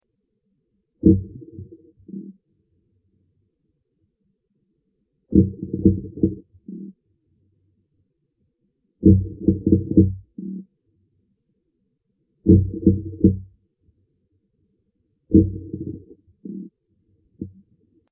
courtship 3
Сигналы ухаживания (= прекопуляционные сигналы, courtship) являются сложнопостроенными фразами, в процессе эмиссии которых самец использует сразу несколько ударно-вибрационных способов возбуждения колебаний.
Третья часть чаще всего состоит из серий, образованных УБ, ВБ и ТР.
К концу фразы интенсивность УБ, ВБ и ТР возрастает.